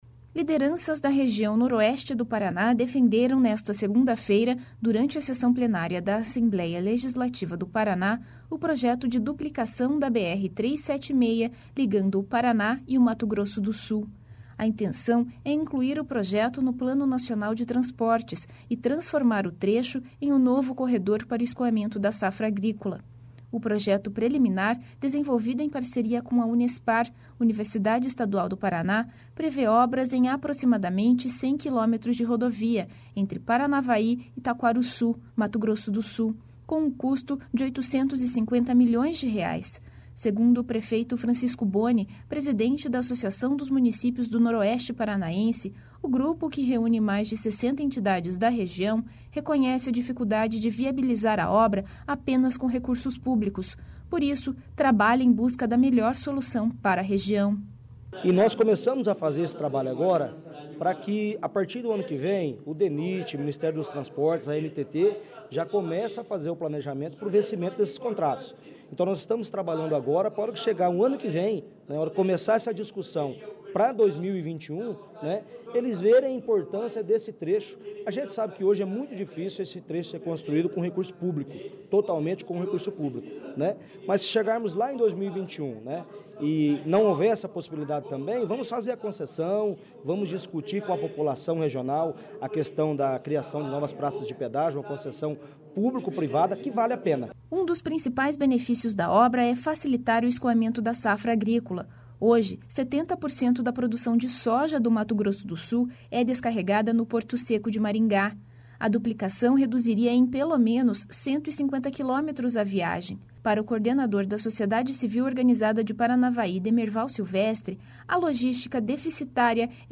((Descrição do áudio))Lideranças da Região Noroeste do Paraná defenderam nesta segunda-feira (14), durante a sessão plenária da Assembleia Legislativa do Paraná, o projeto de duplicação da BR-376, ligando o Paraná e o Mato Grosso do Sul.